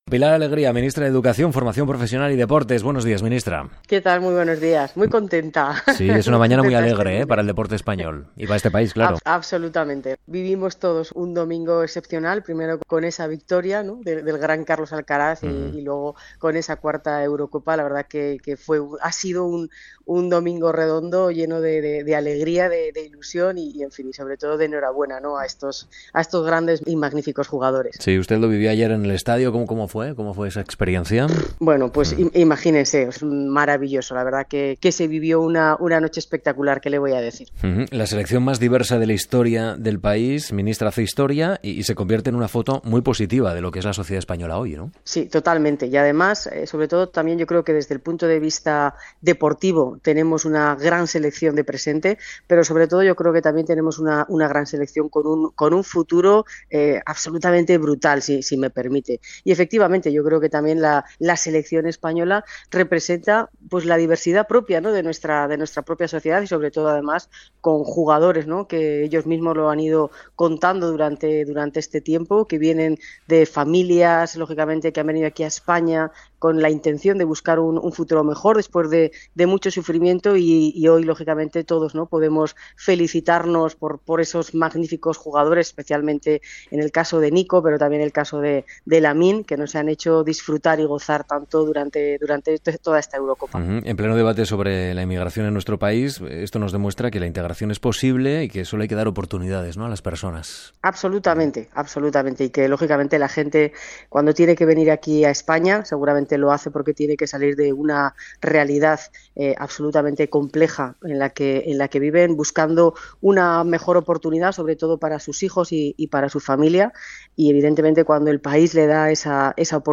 - Las mañanas de RNE - Josep Cuní - 15/07/2024... entrevista a Pilar Alegría, Ministra de Educación, Formación Profesional y Deporte, a raíz de la victoria de la Roja en la Eurocopa.